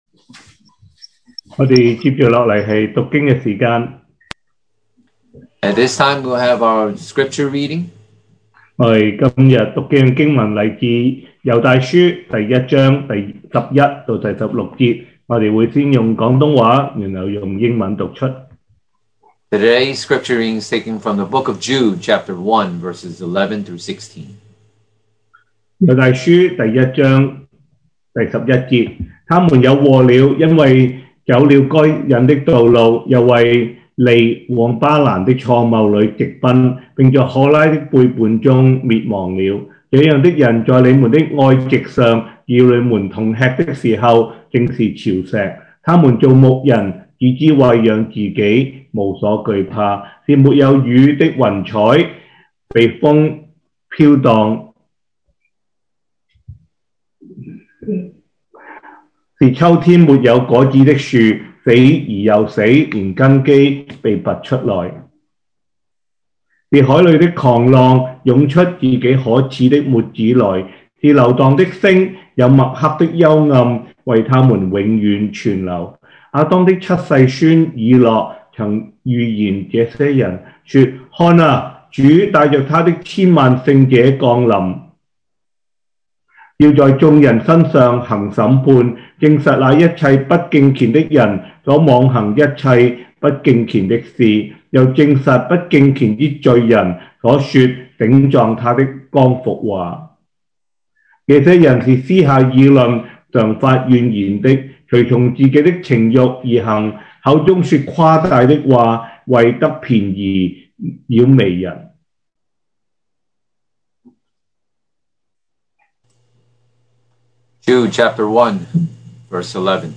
2021 sermon audios
Service Type: Sunday Morning